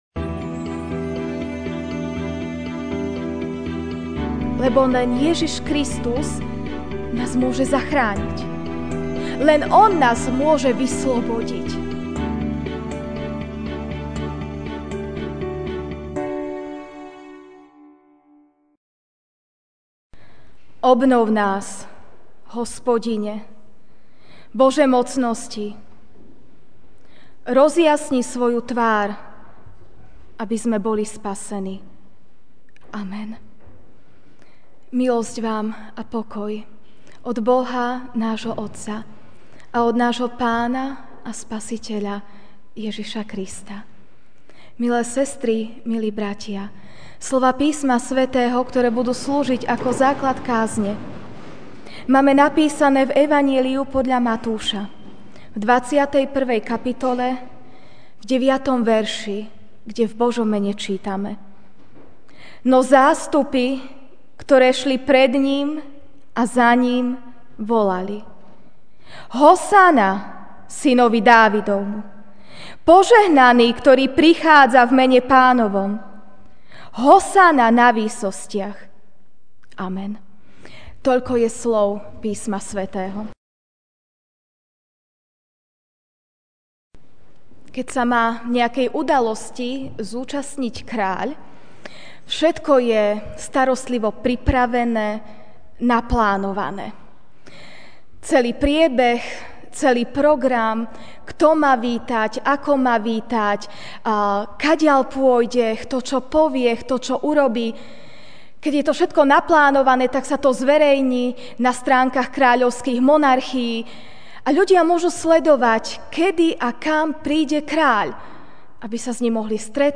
MP3 SUBSCRIBE on iTunes(Podcast) Notes Sermons in this Series Ranná kázeň: Hosana!?